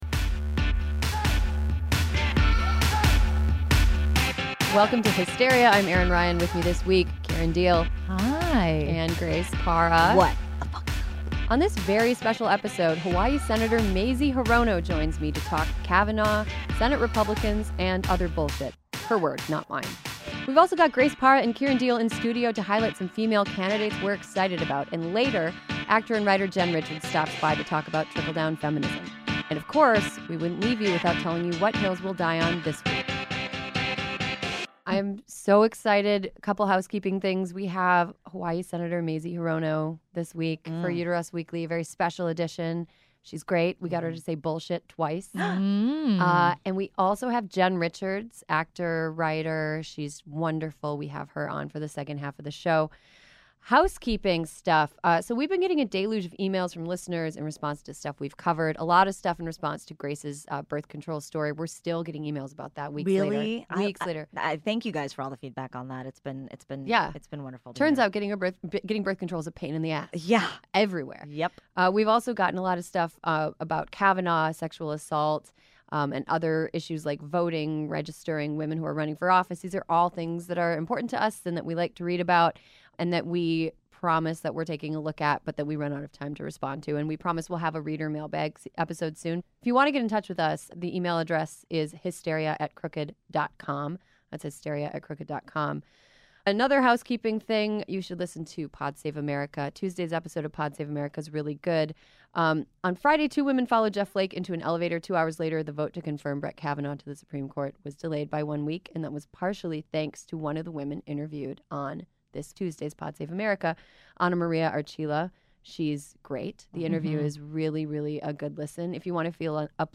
But it’s not all bad: they also focus on some good news, introducing you to some inspiring female candidates who could help flip seats from red to blue. And speaking of inspiring: Senator Mazie Hirono of Hawaii joins us to break down the “bullshit” of this head-exploding moment in history.